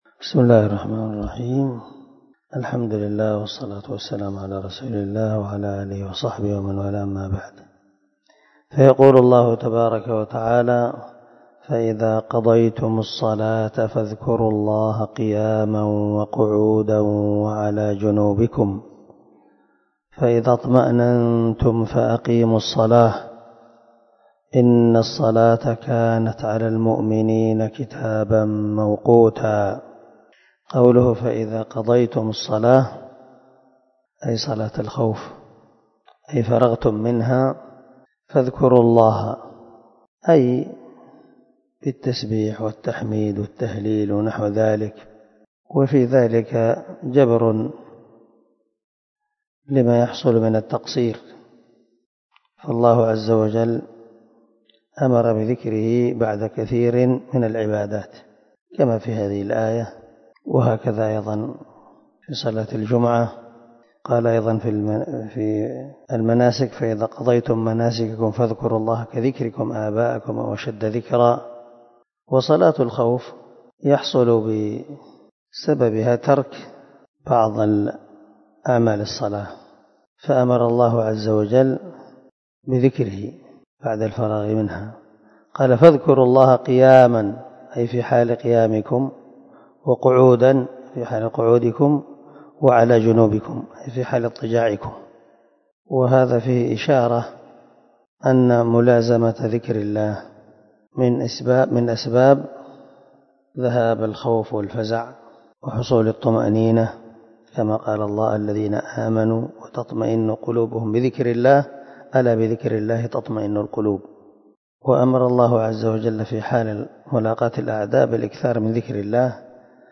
301الدرس 69 تفسير آية ( 103 ) من سورة النساء من تفسير القران الكريم مع قراءة لتفسير السعدي